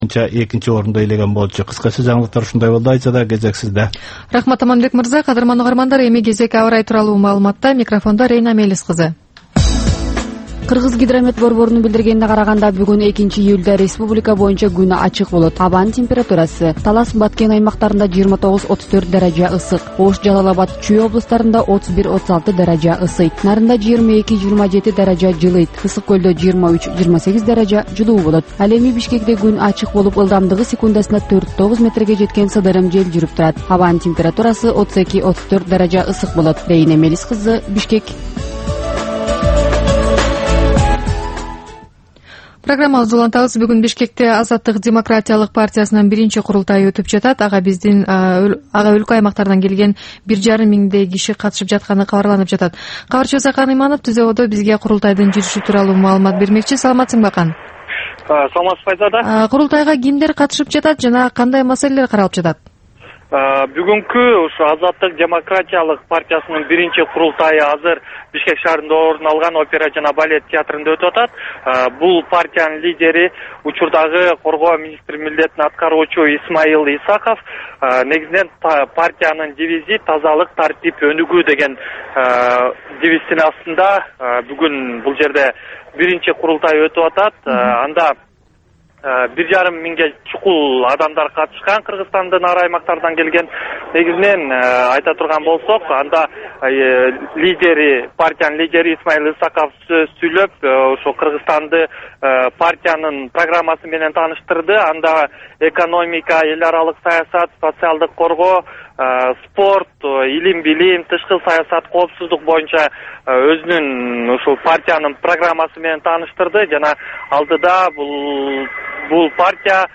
Бул түшкү үналгы берүү жергиликтүү жана эл аралык кабарлар, ар кыл орчун окуялар тууралуу репортаж, маек, талкуу, кыска баян жана башка оперативдүү берүүлөрдөн турат. "Азаттык үналгысынын" бул чак түштөгү алгачкы берүүсү Бишкек убакыты боюнча саат 12:00ден 12:15ке чейин обого чыгарылат.